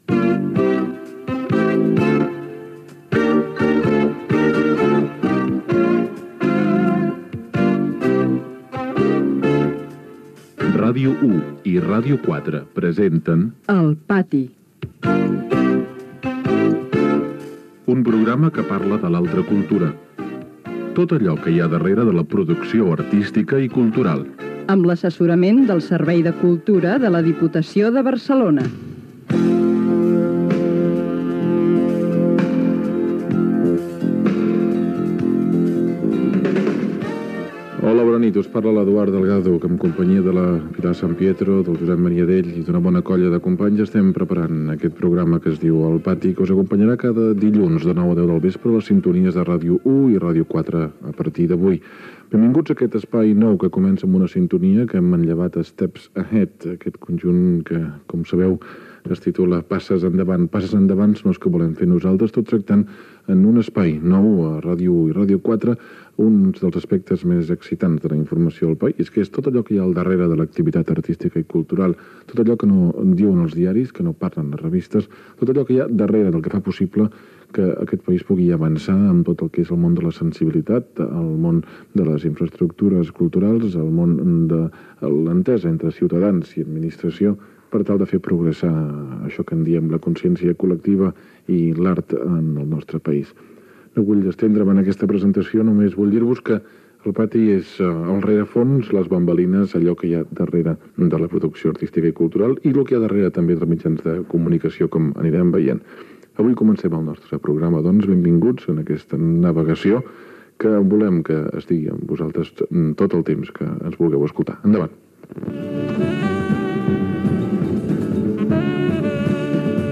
Primera edició del programa. Careta del programa, presentació, equip, objectiu de l'espai, entrevista al president de la Diputació de Barcelona Antoni Dalmau sobre l'activitat cultural de la institució